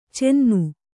♪ cennu